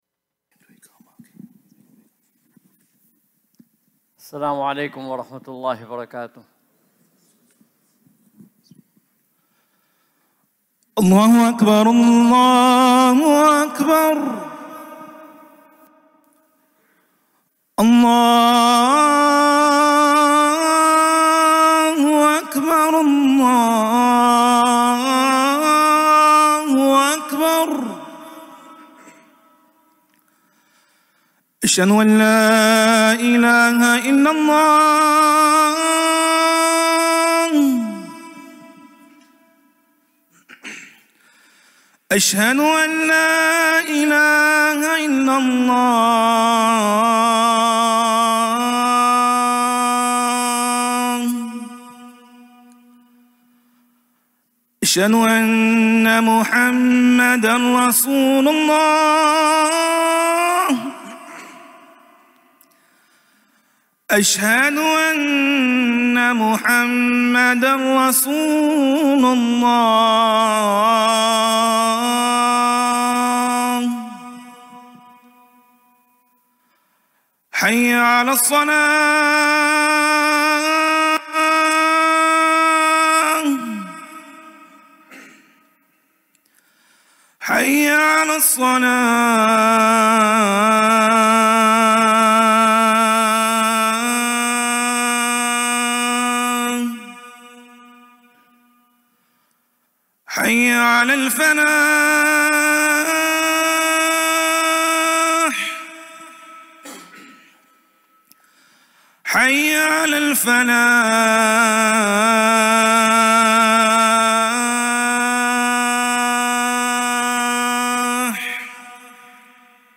Friday Khutbah - "Welcoming Ramadan"